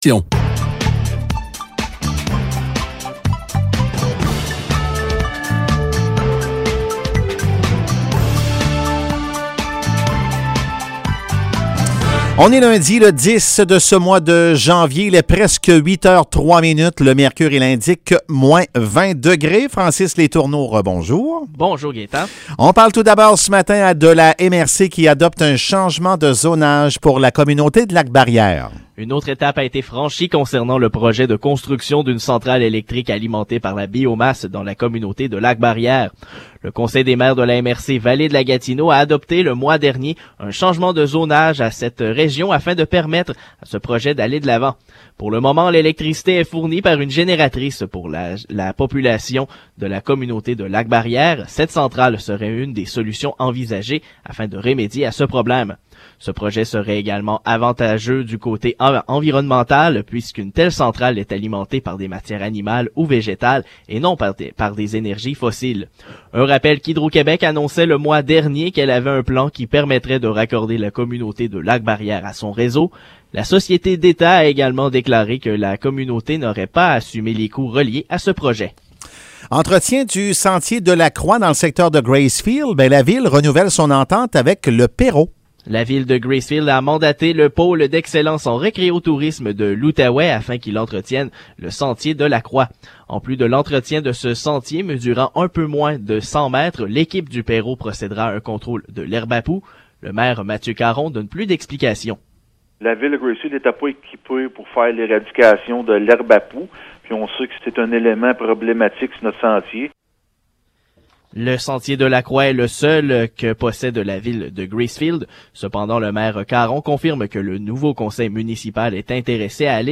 Nouvelles locales - 10 janvier 2022 - 8 h